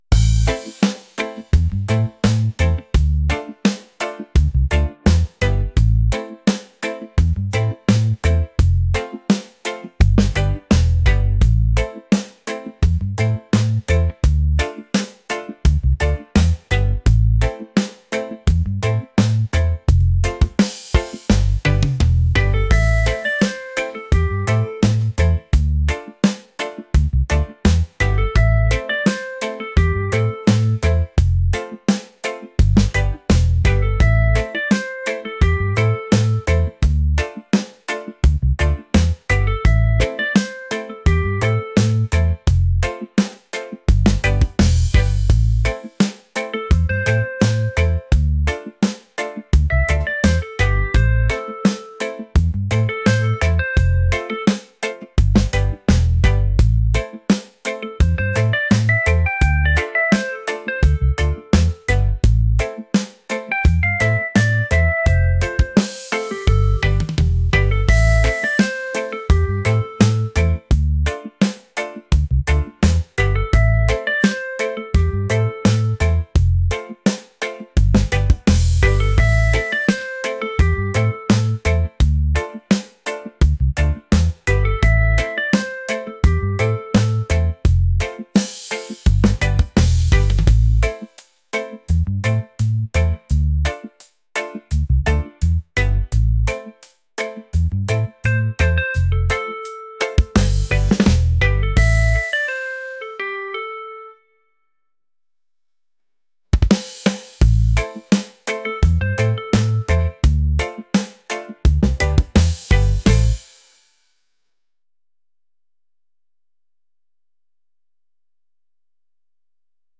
groovy | laid-back | reggae